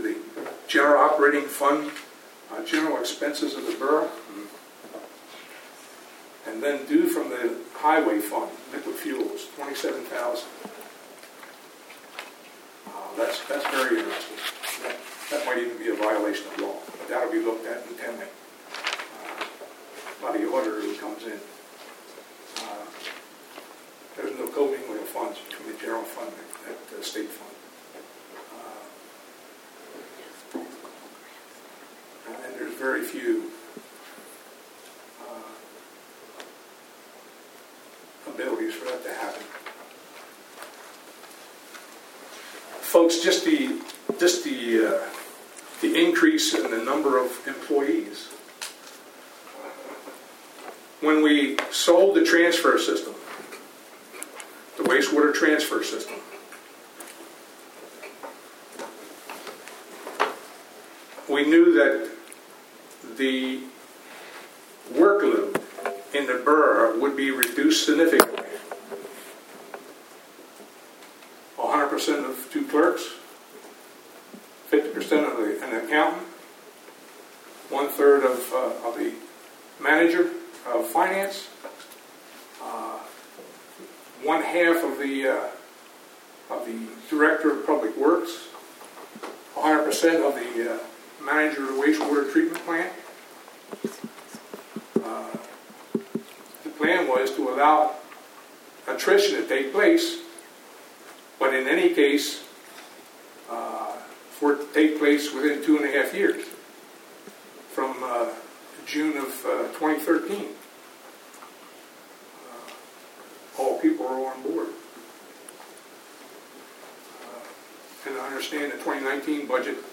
During the meeting, several citizens spoke about issues on the preliminary agenda.